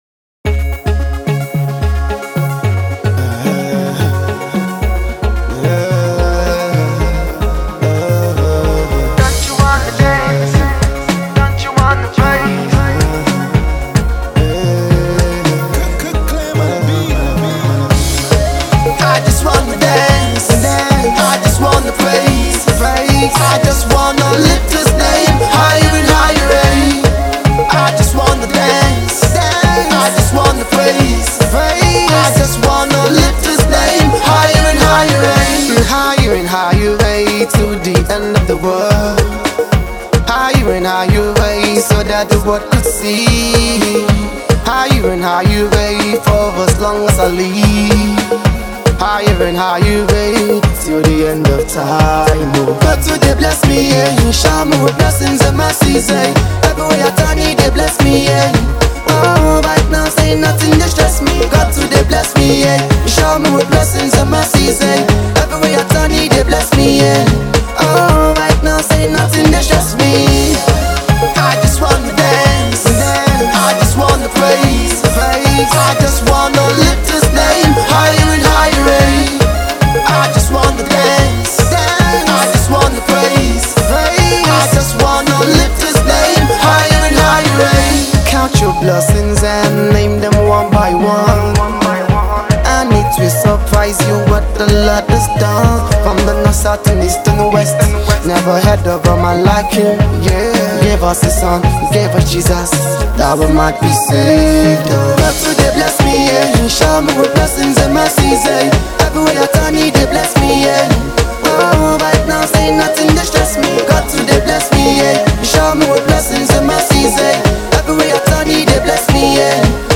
Gospel song
gospel singer